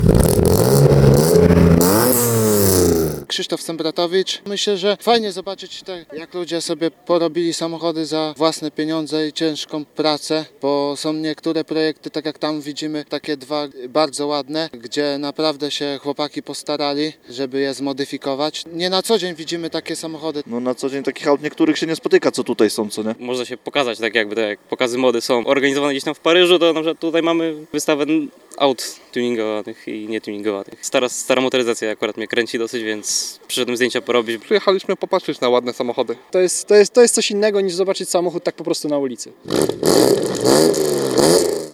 Nieformalna grupa ,,Miłośników Aut” zorganizowała na parkingu przy nowej części cmentarza zlot samochodów.